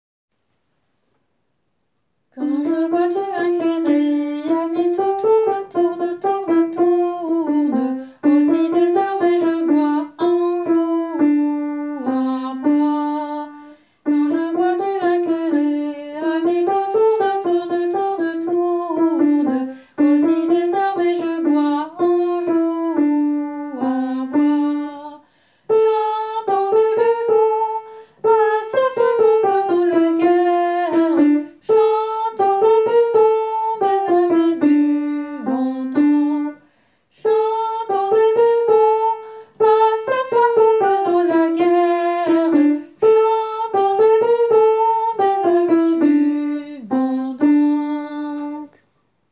Soprano :
tourdion-superius.wav